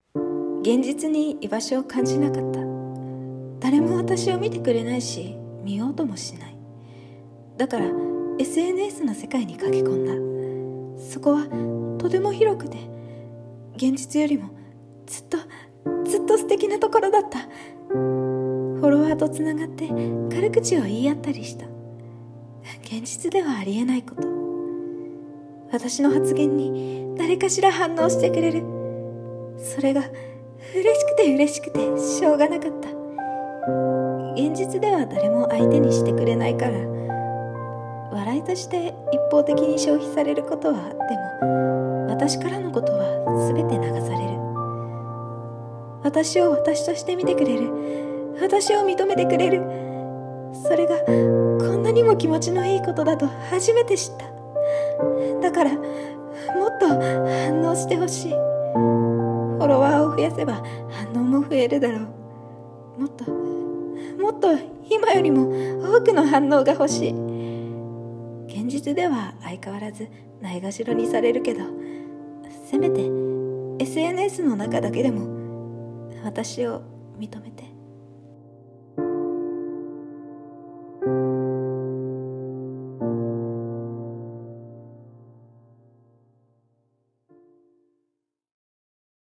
【声劇台本】承認欲求